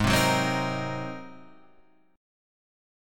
G# 9th